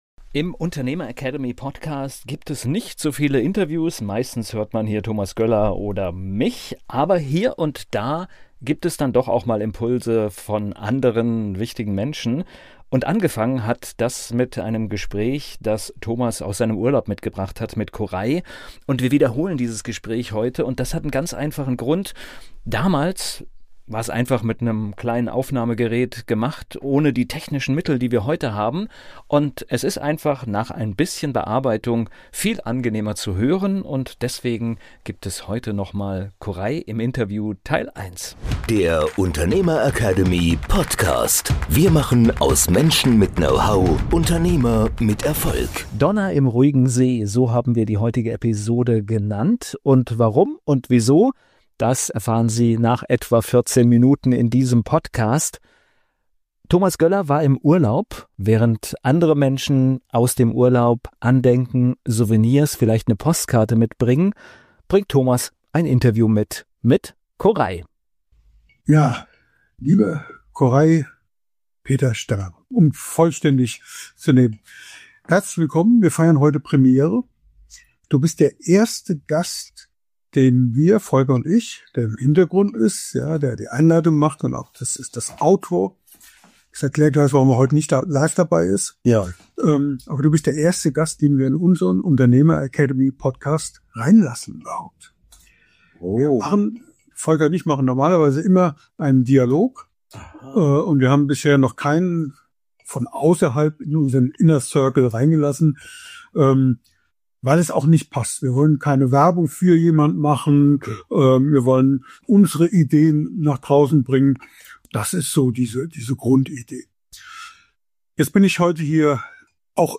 Beschreibung vor 3 Monaten In dieser Episode präsentieren wir eine besondere Wiederholung – allerdings in völlig neuer, überarbeiteter Tonqualität.
Die ursprüngliche Aufnahme entstand damals ganz unkompliziert im Urlaub, mit begrenzter Technik. Heute bringen wir dieses Gespräch noch einmal – klarer, angenehmer und inhaltlich zeitlos relevant.
Eine Episode voller Inspiration, Klarheit und eindrucksvoller Lebenswege – nun erstmals in wirklich angenehmer Klangqualität.